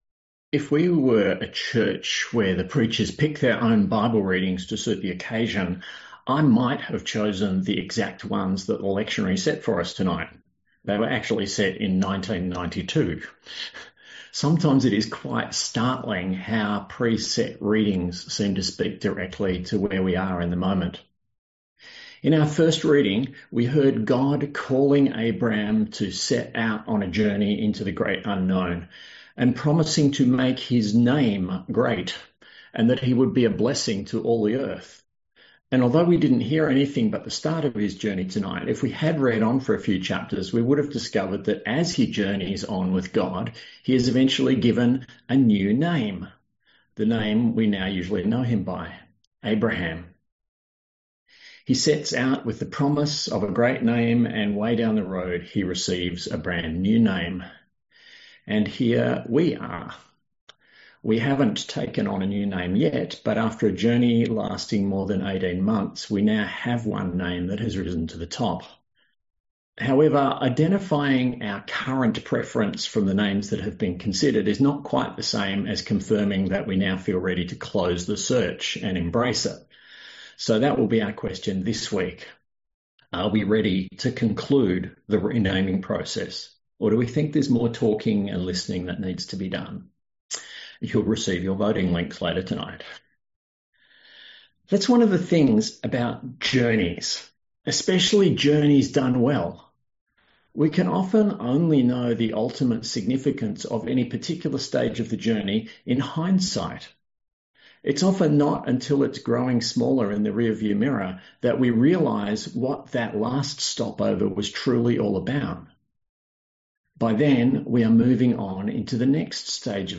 A sermon on Genesis 12:1-4a; Psalm 121; & John 3:1-17